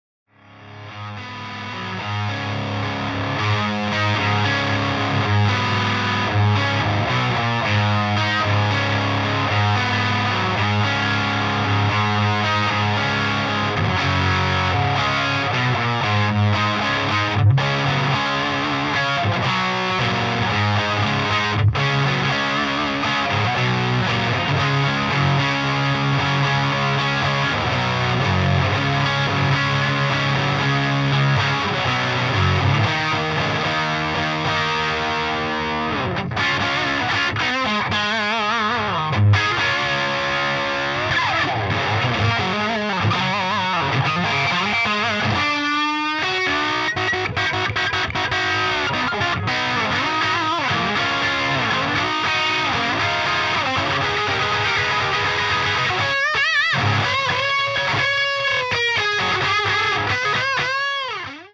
me suis rendu compte tout a l'heure avec grand plaisir que du coup je peux equaliser de maniere analogique avec la table direct à la prise des grattes. alors j'ai fait un test grossier ou j'ai abusé du medium pour voire si ça rendait bien si on abusait sur une freq
alors du coup c'est too much bien sur, mais ça me convainc encore plus, aucun plugins me donne un relief, un medium chaleureux comme ça !
c'est mon jcm800 avec trop de gain :
ça degueule c'est vivant c'est l'antithese de modelisateur + ir avec son coté bcp trop propret.